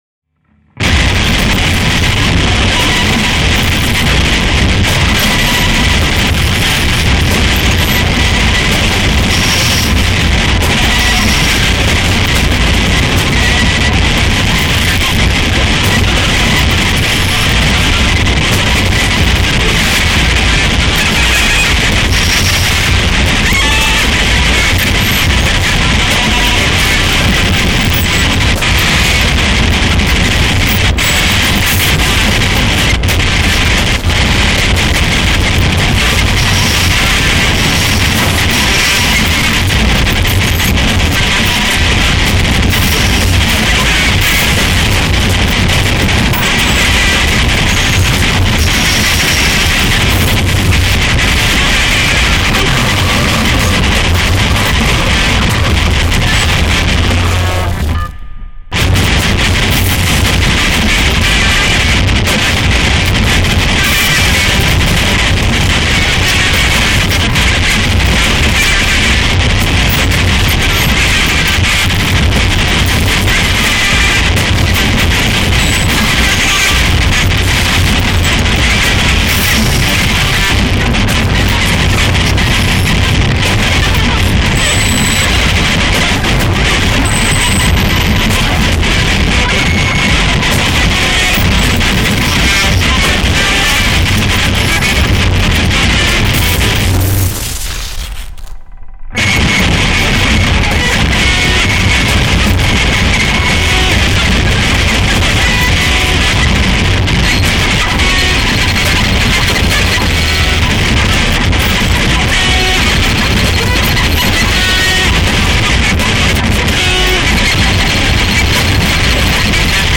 guitar, prepared guitar
analog synth, electronics, processed tapes
at his home studio in Naples, Italy